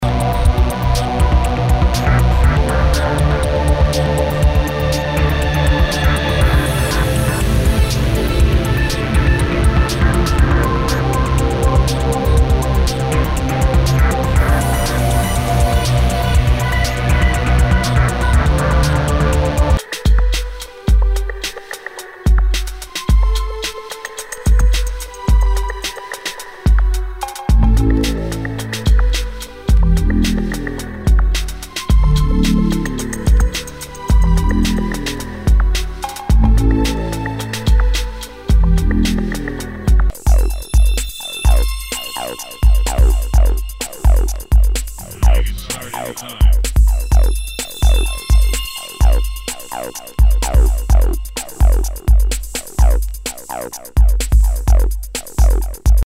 Nu- Jazz/BREAK BEATS
IDM / ダウンテンポ / エレクトロ！！